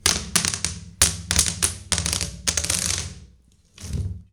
household
Shower Plastic Carpet Pulling Off Slowly